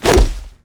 grenade throw.wav